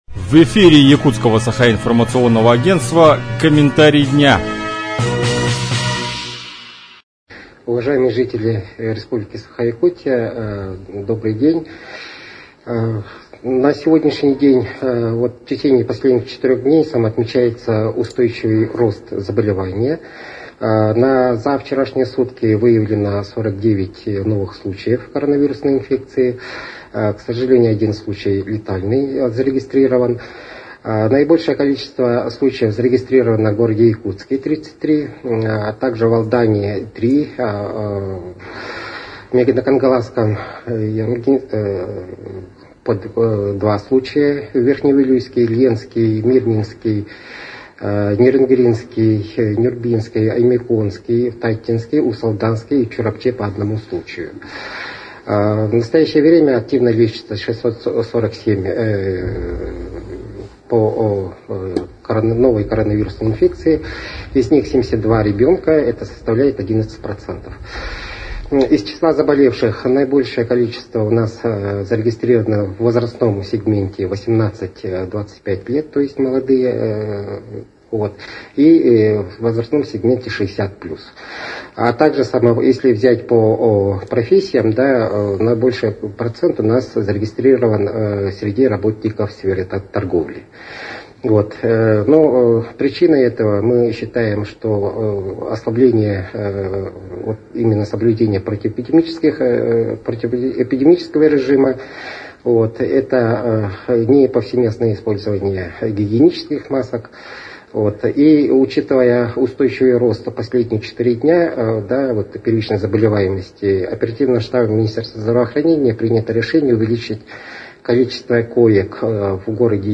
Об обстановке в Якутии на 16  апреля рассказал первый заместитель министра здравоохранения Якутии Алексей Яковлев.